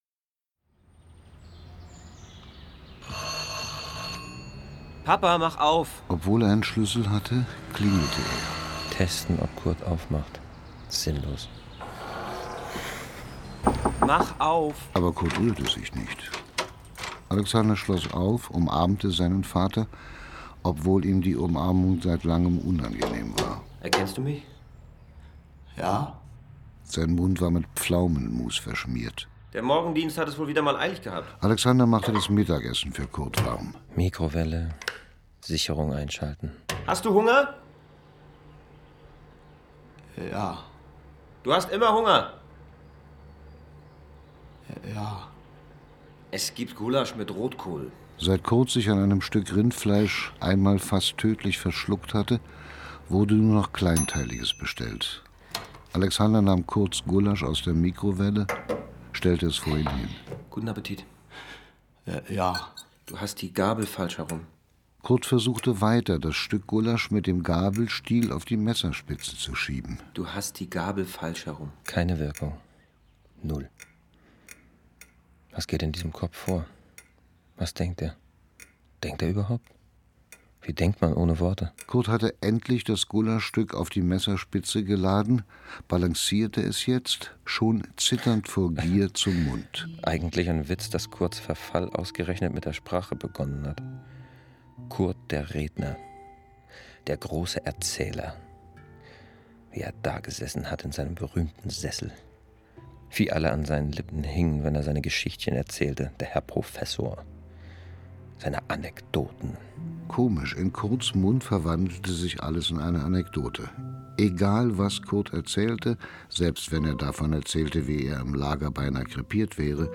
Hörspiel (Produktion SWR)
Dieter Mann, Eva-Maria Hagen, Devid Striesow (Sprecher)